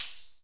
TAMB#1   MPC.wav